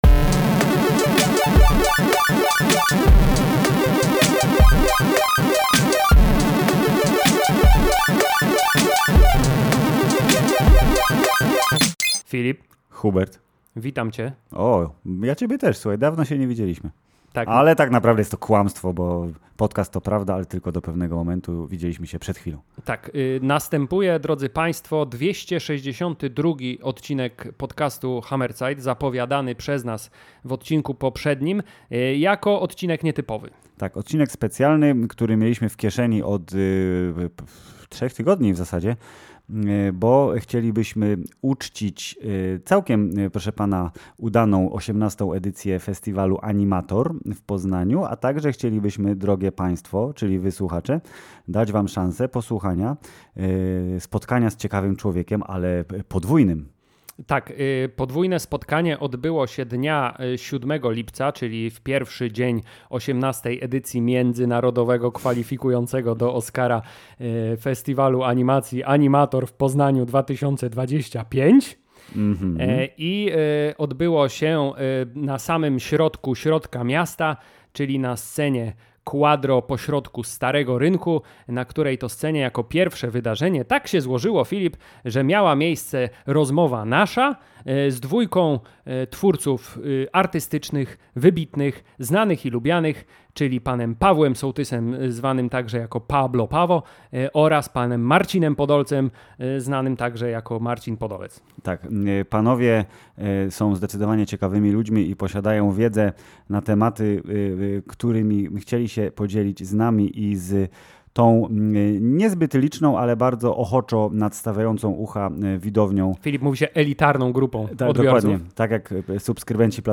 … continue reading 432 episodes # Popkultura # Społeczeństwo # Polski # Film # Filmy # Seriale # Recenzje # Rozmowy # Kino # Serial # Rozrywka # Hammerzeit # Polsku